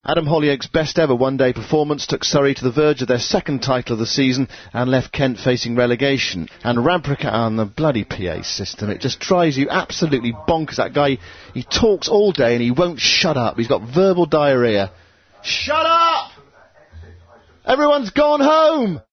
Radio Bloopers
A bad day at the office just got noisier for a very frustrated reporter.
A loud hailer